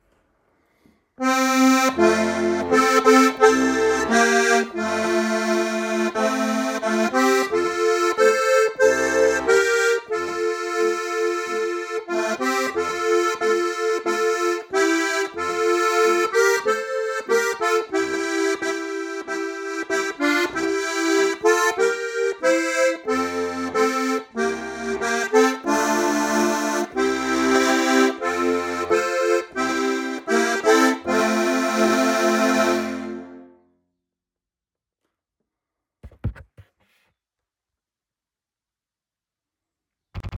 ICH HATT' EINEN KAMERADEN Akkordeon
Eine bekannte Volksweise aus dem Jahre 1809.